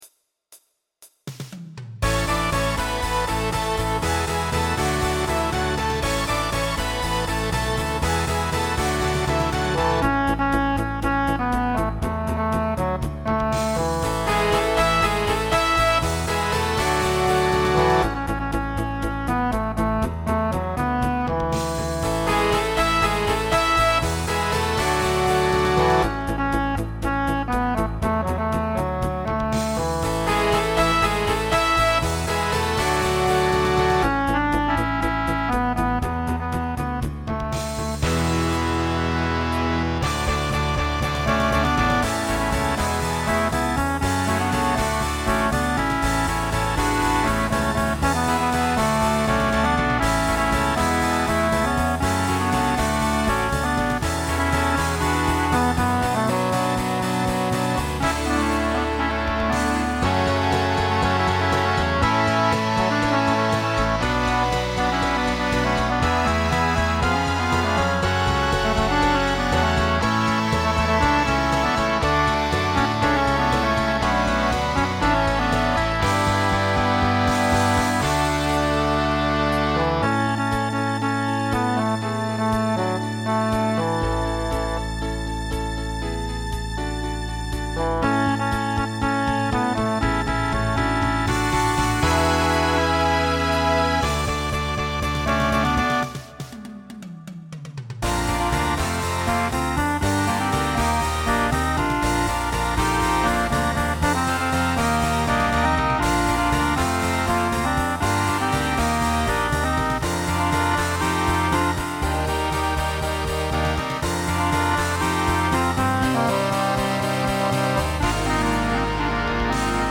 Voicing TTB Instrumental combo Genre Pop/Dance , Rock